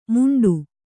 ♪ muṇḍu